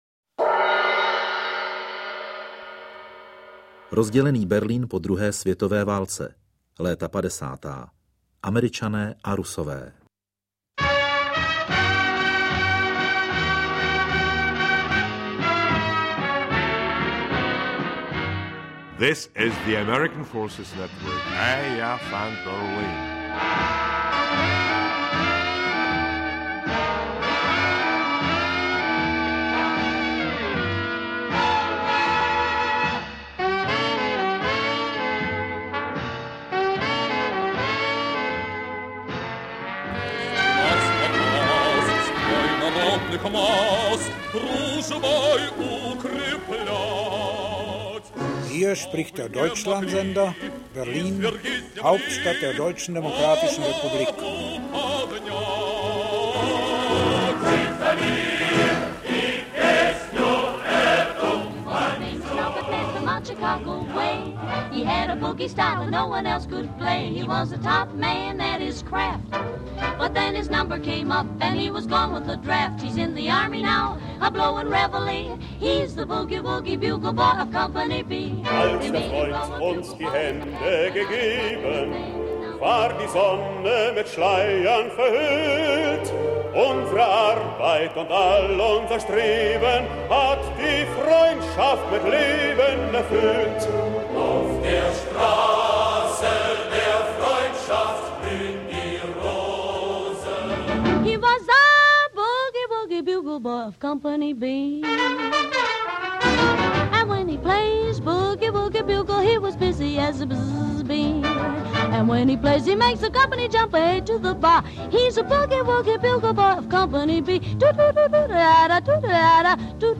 Interpret:  Ivan Trojan
AudioKniha ke stažení, 5 x mp3, délka 2 hod. 5 min., velikost 114,5 MB, česky